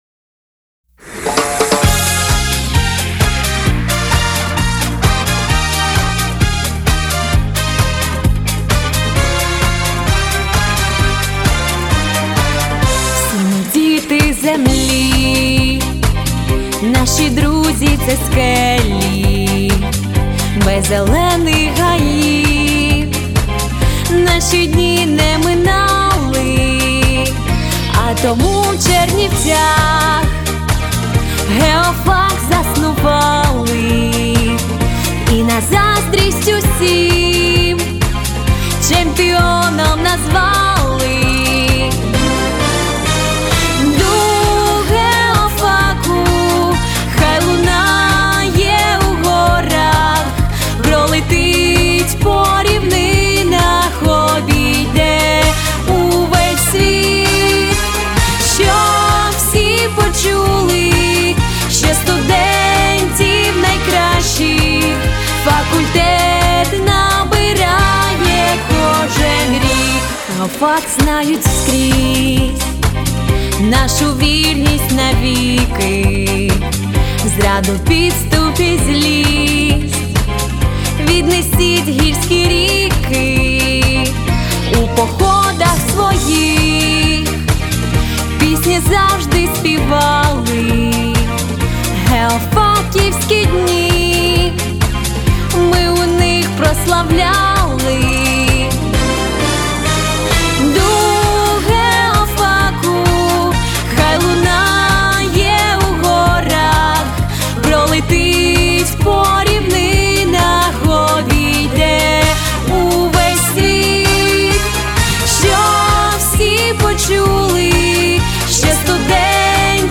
Гімн
Музика - Володимир Івасюк
Текст - Юлія Воєвідко-Семієнчева